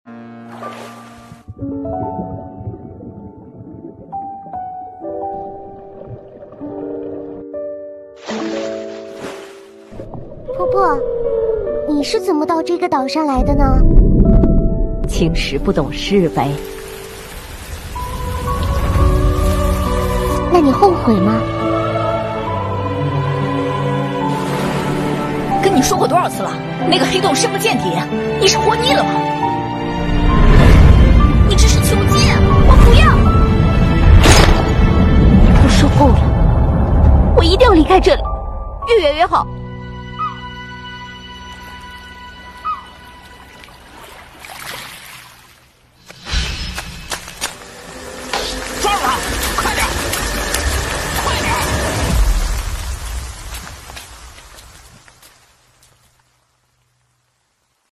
女童 少女 老年女